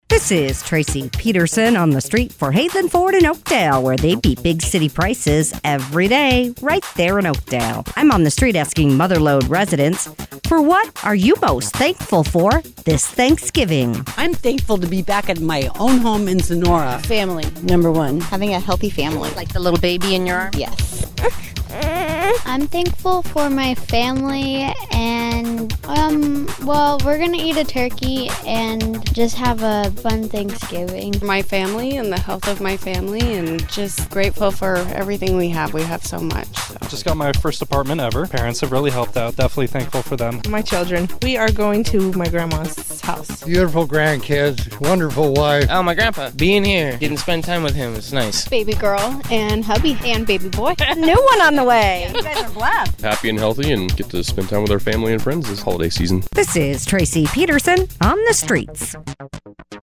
asks Mother Lode residents, “For what are you most thankful this Thanksgiving?”